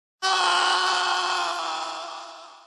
Dark Souls/Bloodborne death SFX, for the meme.